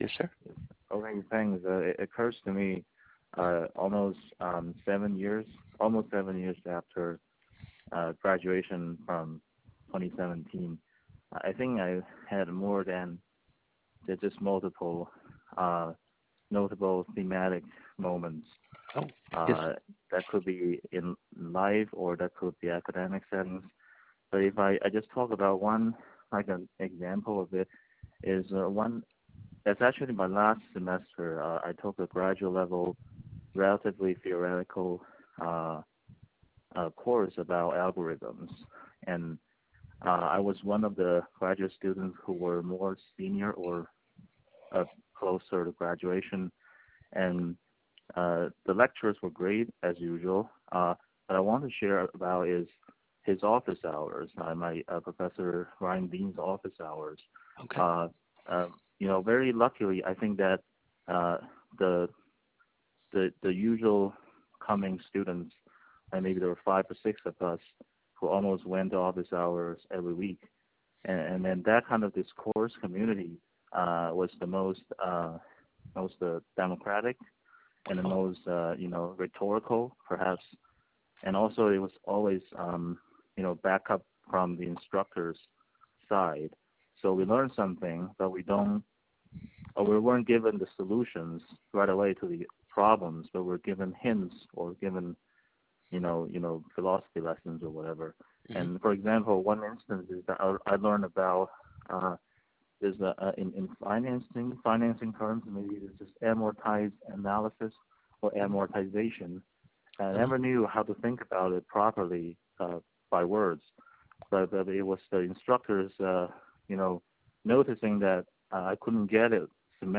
Academic Influences. Tiger Stories: 2024 Clemson University Oral History Project.